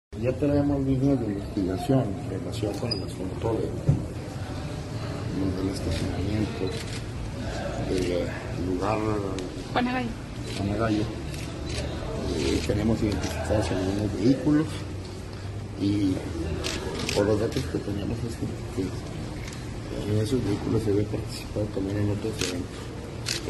AUDIO: CÉSAR JÁUREGUI MORENO, FISCAL GENERAL DEL ESTADO (FGE) Chihuahua, Chih.- El fiscal General del Estado, César Júaregui Moreno, abordó los avances en investigaciones, diligencias y peritajes, sobre los homicidios registrados en el día de ayer 28 de julio, en el « Bar Juana Gallo «, el cual consideró como resultado de una riña al exterior del centro nocturno.